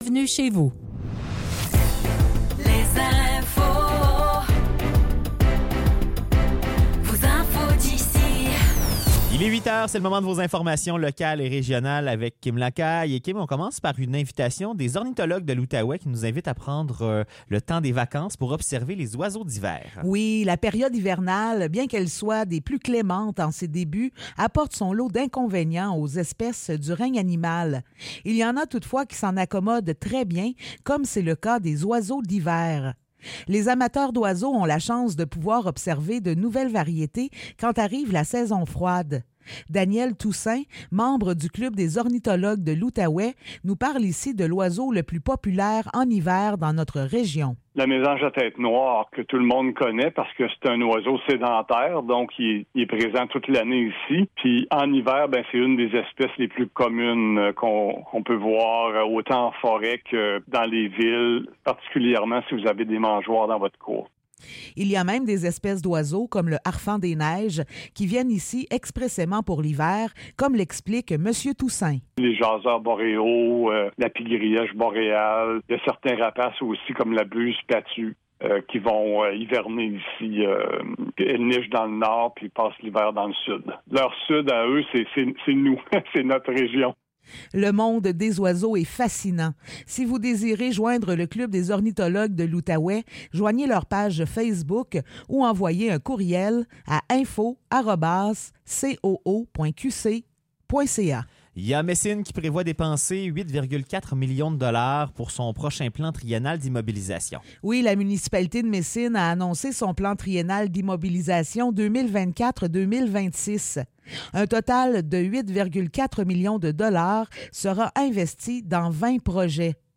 Nouvelles locales - 29 décembre 2023 - 8 h